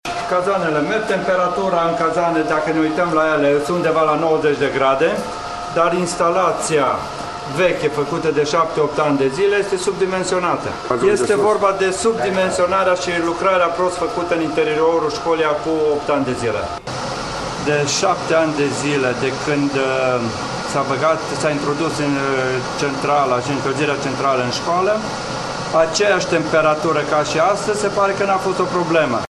Primarul din Păuliş, Ioan Turcin: